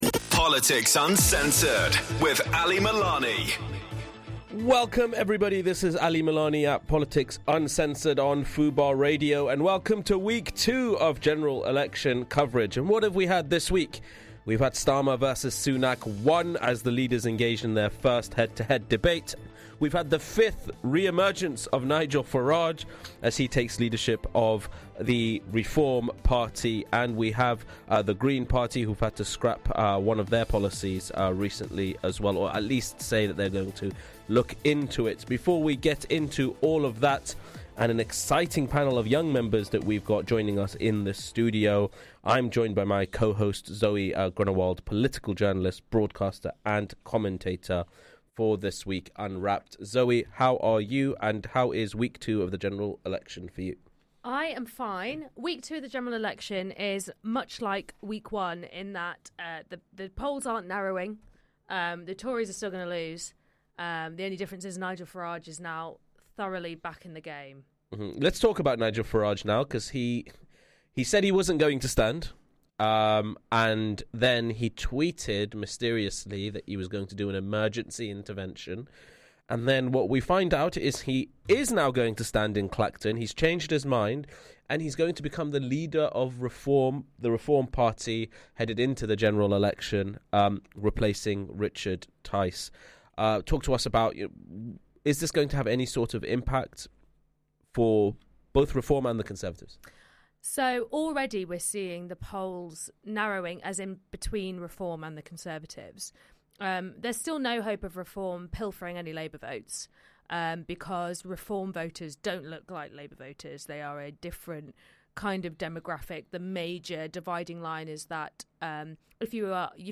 Episode 48- Young activist roundtable - Politics Uncensored